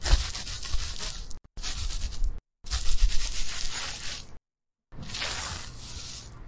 短暂的声音效果 " Raschel kurz 2
描述：短暂的沙沙作响
Tag: SFX sounddesign 弗利